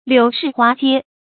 柳市花街 注音： ㄌㄧㄨˇ ㄕㄧˋ ㄏㄨㄚ ㄐㄧㄝ 讀音讀法： 意思解釋： 指妓院云集的地方 出處典故： 清 黃六鴻《福惠全書 郵政 逐娼妓》：「多置狎邪門巷，遂作鶯巢燕壘，頓成 柳市花街 。」